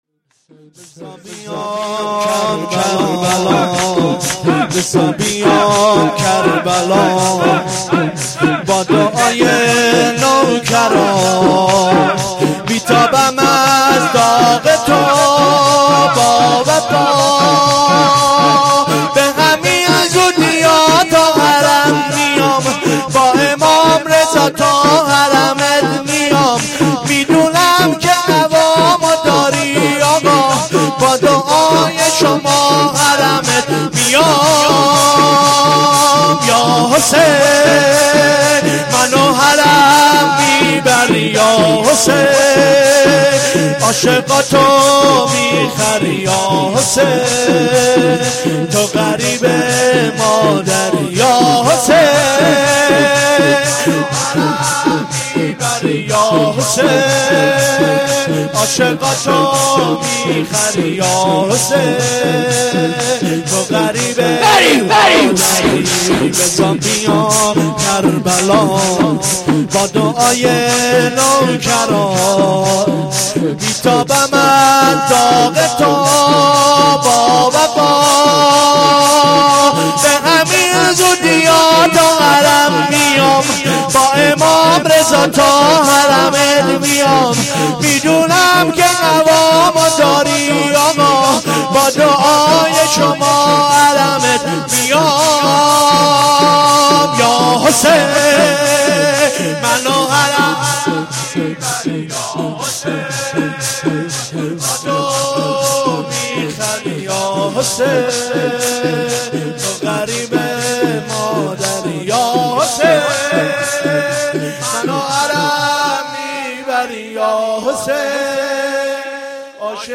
شور3